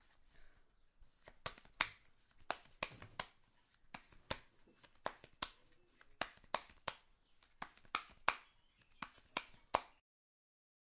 洗牌
描述：卡片以各种速度洗牌，几乎就像赌场赌博一样。